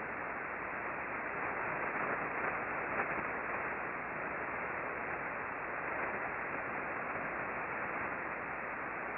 We used the Icom R-75 HF Receiver tuned to 20.408 MHz (LSB).
Click here for a 9 second recording of the bursting at 1043